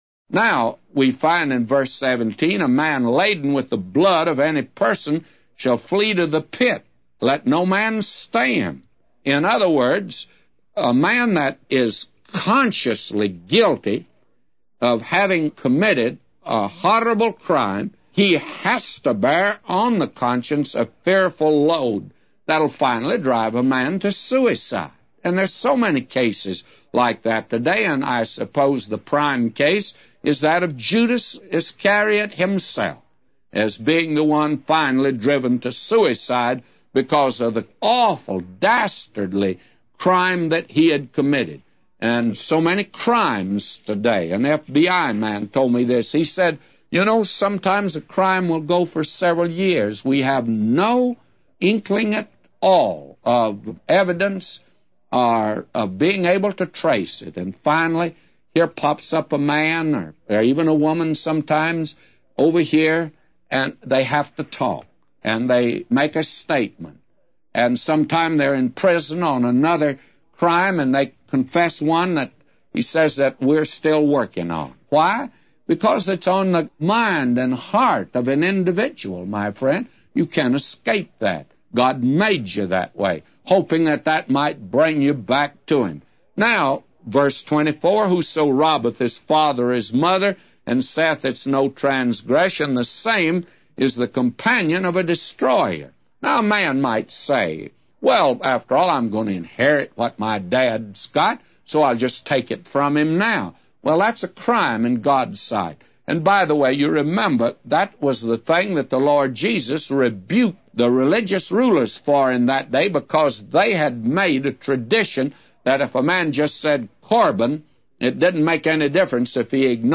A Commentary By J Vernon MCgee For Proverbs 28:17-999